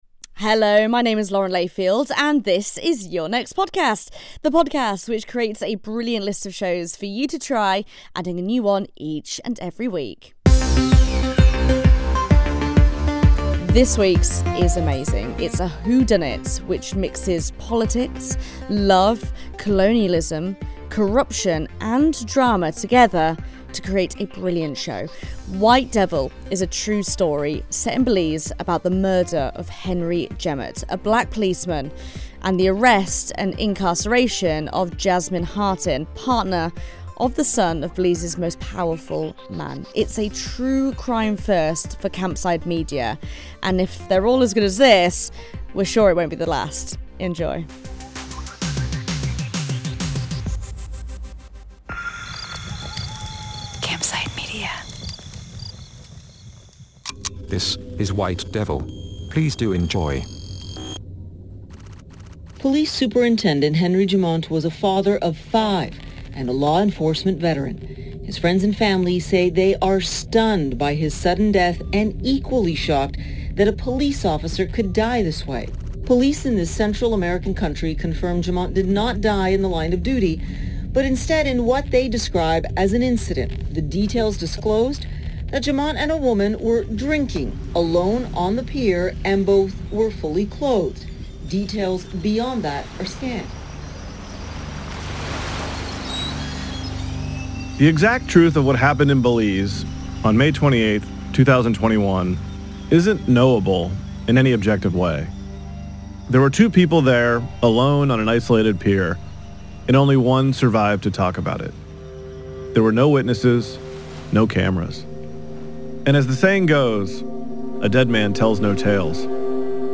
Lauren Layfield introduces White Devil on the podcast recommendation podcast - Your Next Podcast.